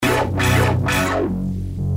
描述：另一个可以采样或循环的摇摆不定的低音。
Tag: 135 bpm Dubstep Loops Bass Loops 342.07 KB wav Key : Unknown